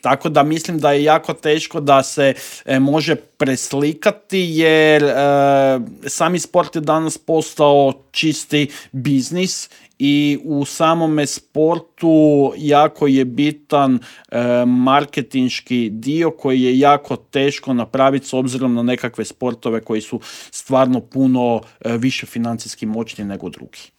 U intervjuu Media Servisa razgovarali smo o "maloj tvornici medaljaša"